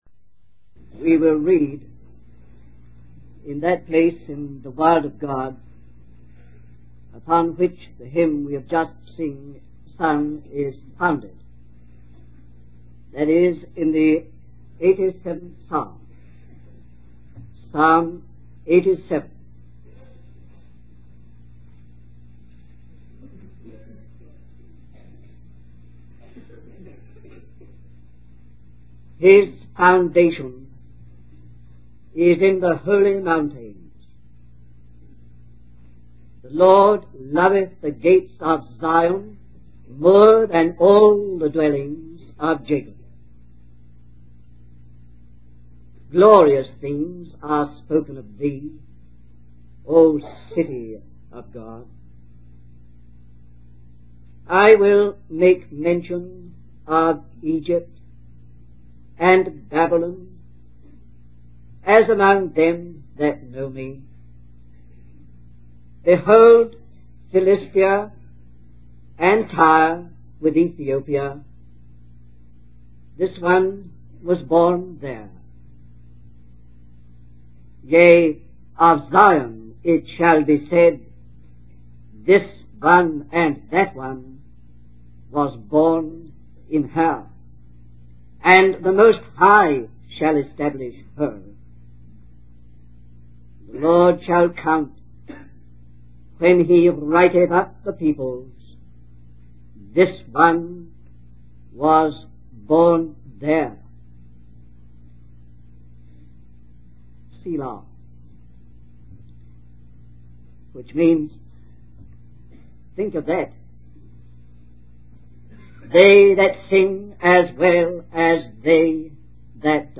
In this sermon, the speaker emphasizes the importance of the citizens of Zion, referring to believers in Christ. He highlights the significance of the gates of the city as places of counsel and decision-making for the people of God.